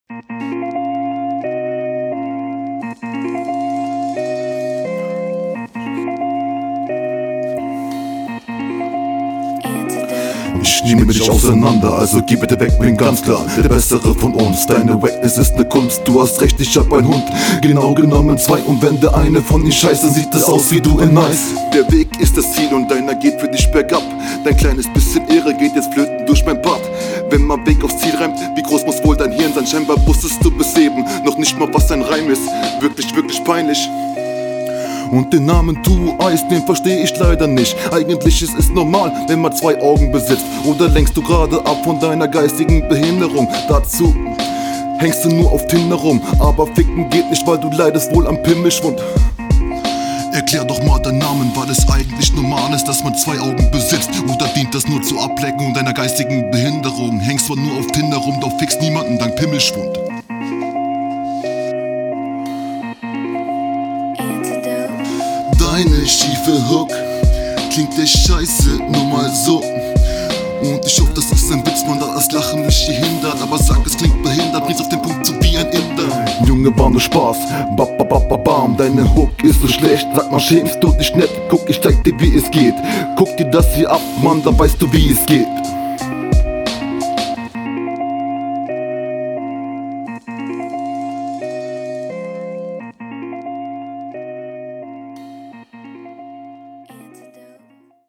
Alter bitte Doubles weg lassen.
der anfang ist schief und die doubels sitzen nicht, klingt irgendwie komisch, du steigerst dich …